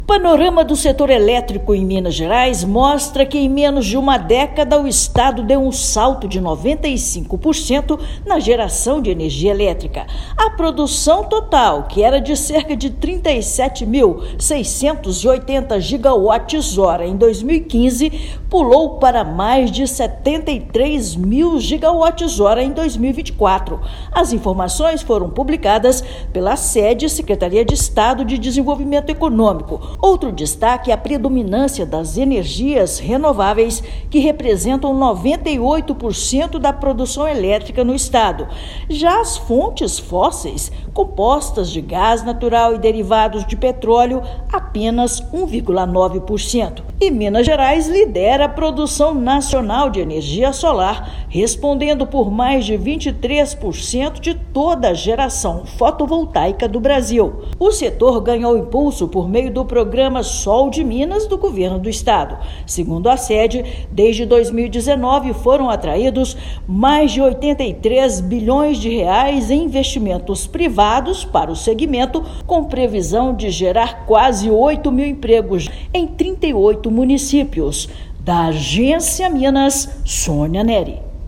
Panorama do Setor Elétrico de Minas Gerais, publicado pela Sede-MG, mostra que estado gerou 73.478 GWh e respondeu por 9,8% da produção nacional em 2024. Ouça matéria de rádio.